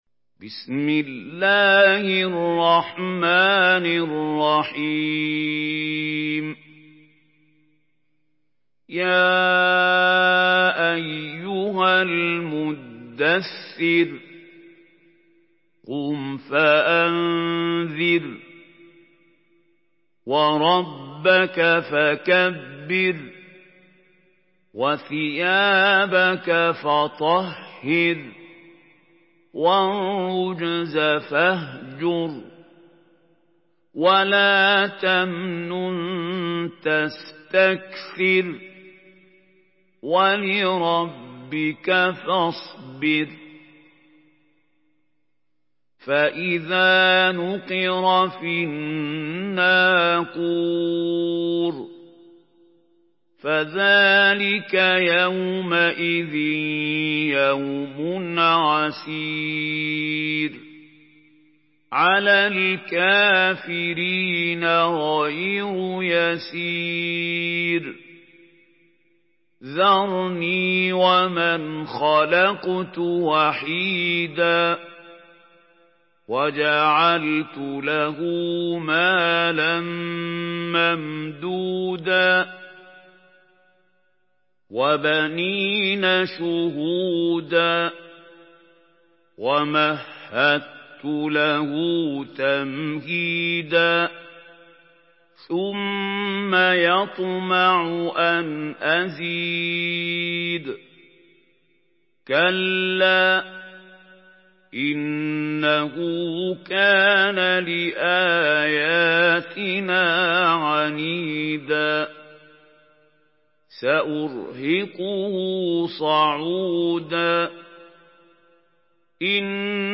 Murattal Hafs An Asim